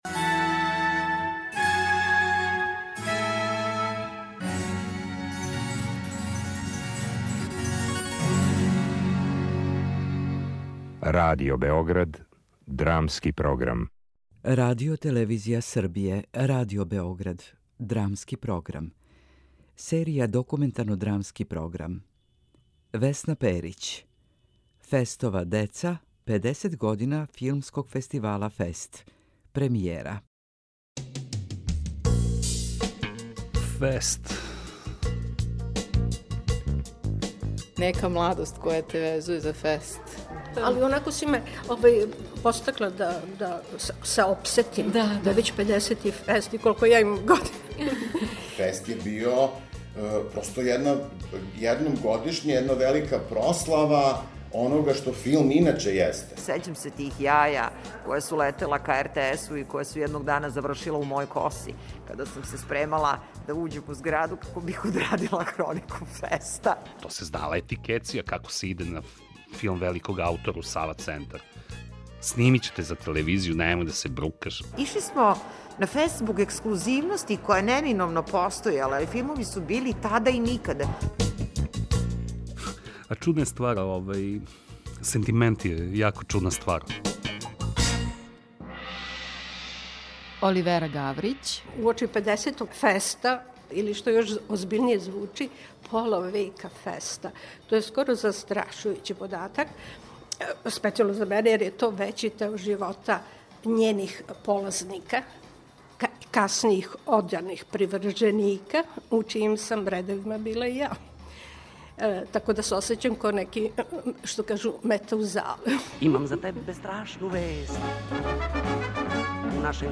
Документарно-драмски програм (премијера)
dokumentarnodramski.mp3